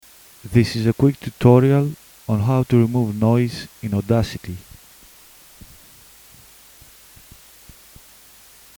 For this example, I have recorded my voice through a microphone that has captured a bit of background noise.
Notice the silence before and after the spoken vocal.
sample-with-noise.mp3